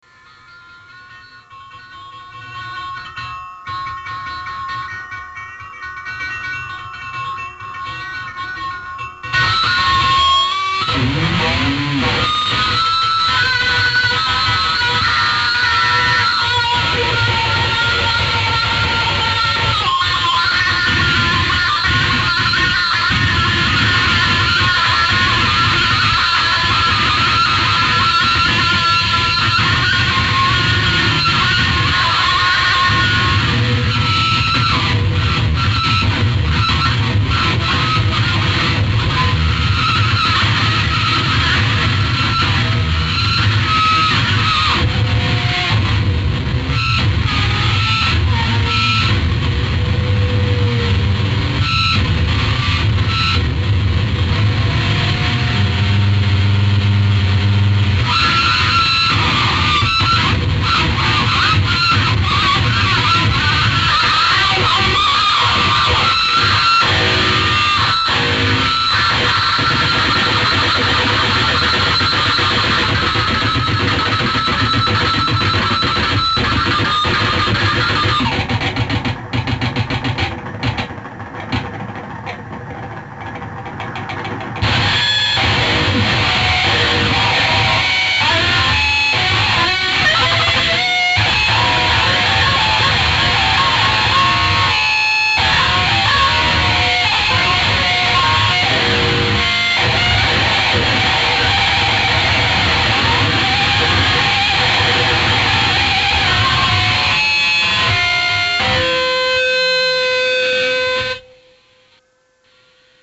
54 pieces of extreme guitar noise.
54 kurze stücke extremer gitarren-noise.